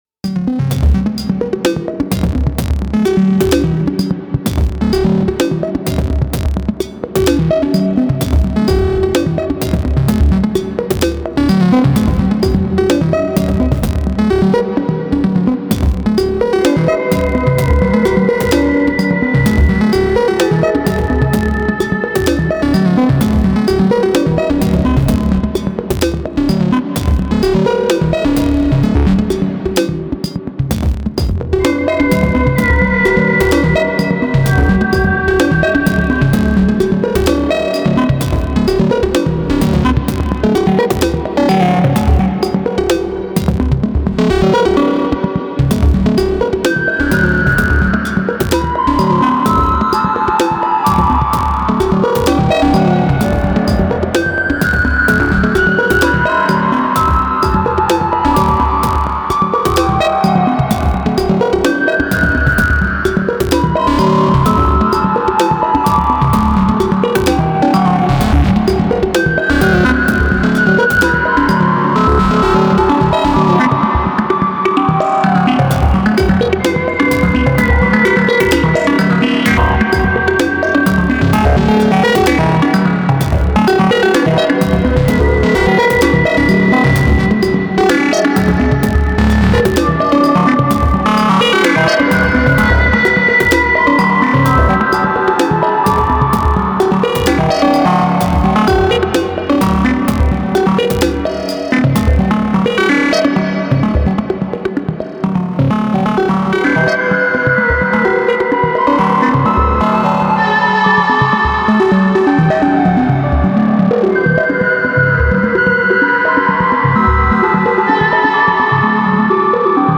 Introducing the 0 Coast to the Digitone